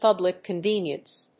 アクセントpúblic convénience